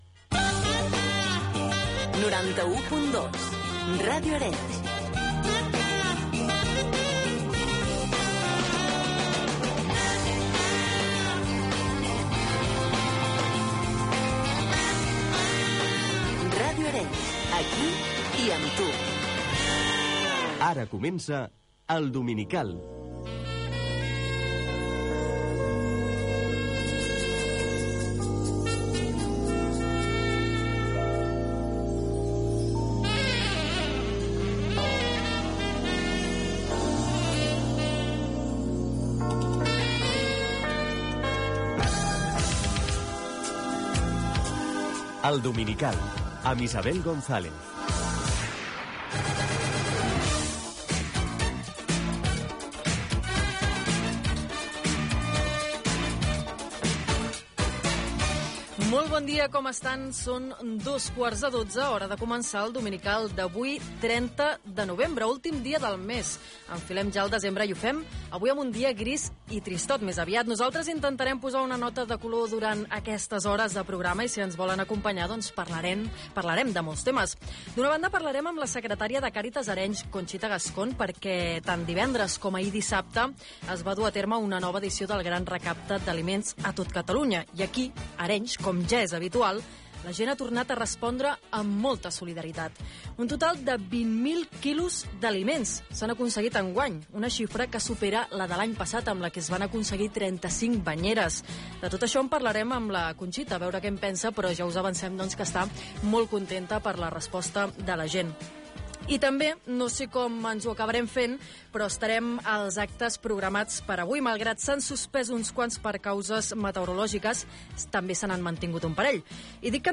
Gènere radiofònic
Entreteniment
FM